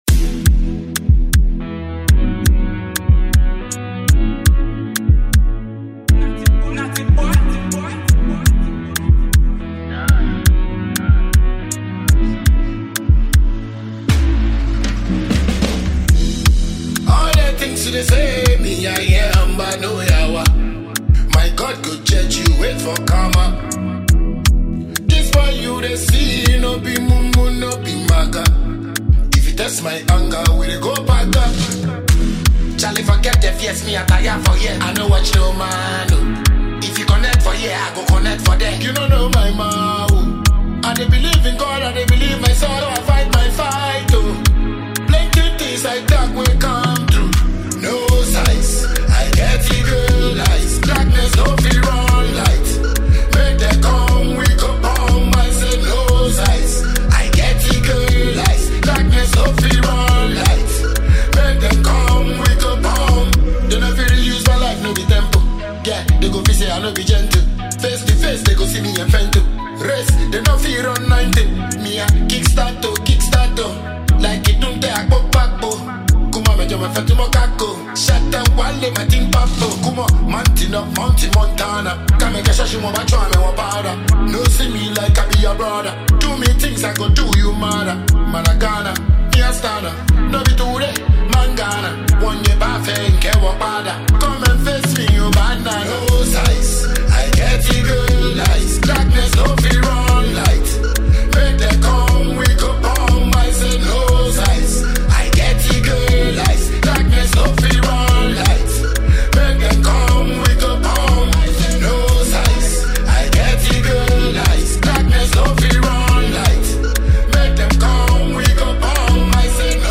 Top Notch Ghanaian dancehall singer and a businessman